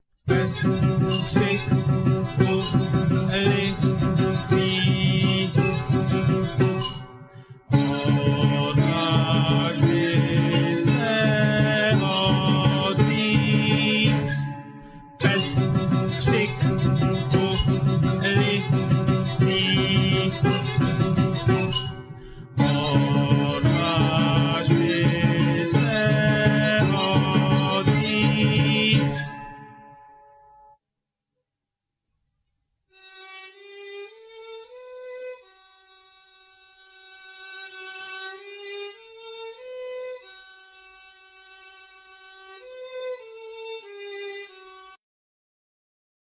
Piano,Vocal,Drum,Vibraphone,Glockenspiel
Violin,Piano,Vocal,Vibraphone,Glockenspiel